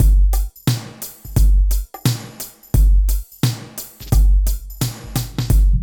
27 DRUM LP-R.wav